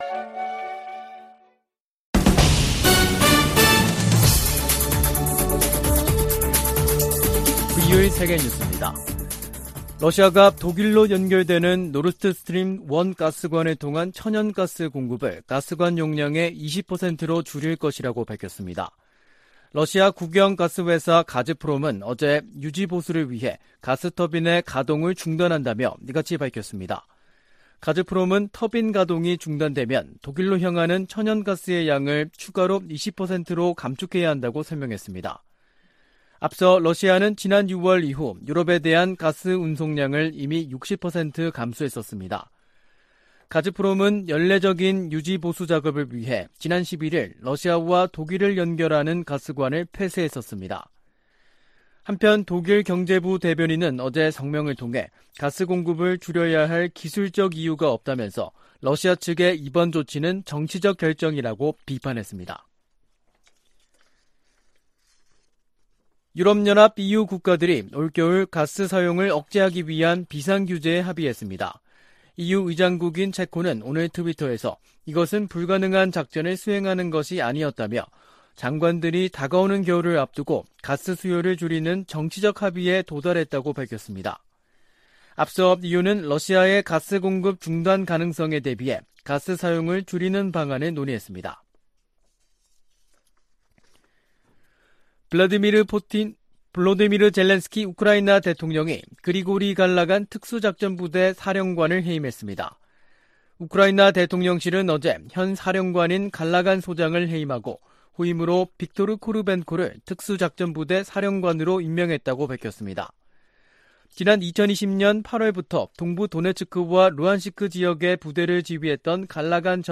VOA 한국어 간판 뉴스 프로그램 '뉴스 투데이', 2022년 7월 26일 3부 방송입니다. 미 국무부는 모든 가용한 수단을 동원해 북한 악의적 사이버 공격 세력을 추적하고 있다고 밝혔습니다. 아미 베라 미 하원의원은 북한의 핵실험을 한일 갈등 극복과 미한일 관계 강화 계기로 삼아야 한다고 말했습니다. 미군과 한국 군이 세계 최강 공격헬기를 동원한 훈련을 실시했습니다.